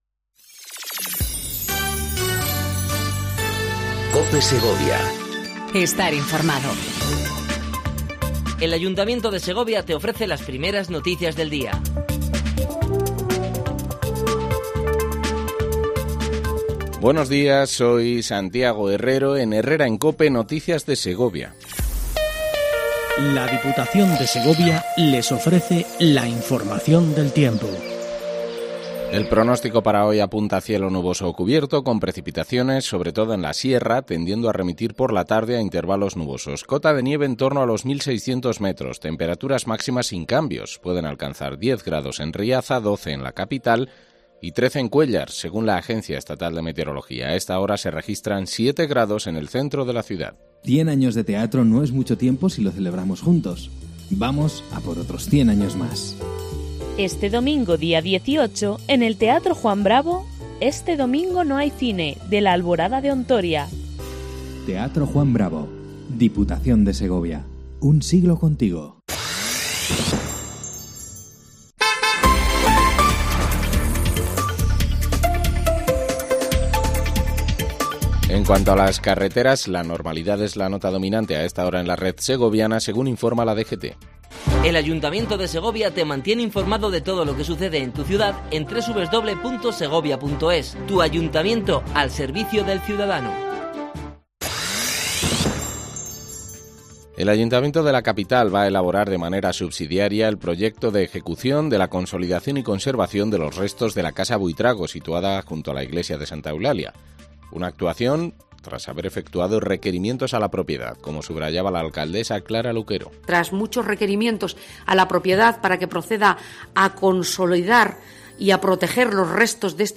INFORMATIVO 07:55 COPE SEGOVIA 19/11/18
AUDIO: Primer informativo local en cope segovia